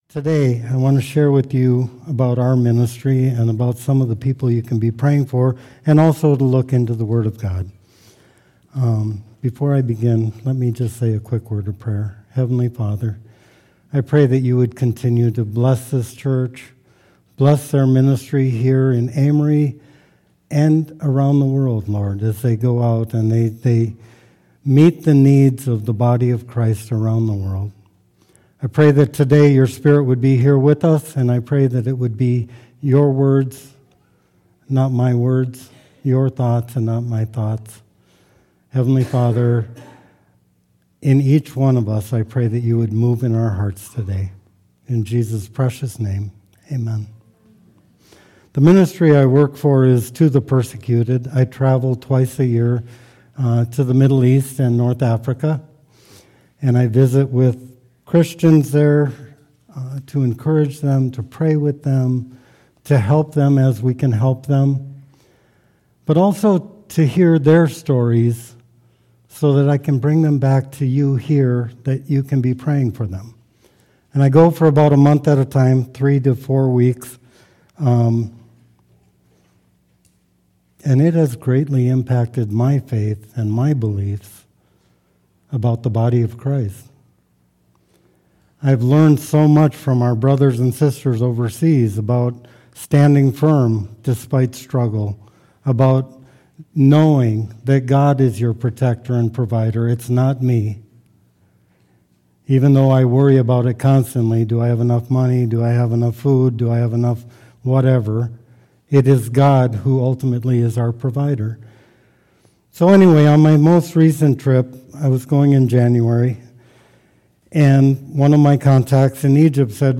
A message from guest speaker